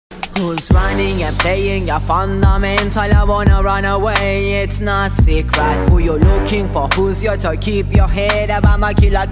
10-saniyelik-sark-kesiti-8.mp3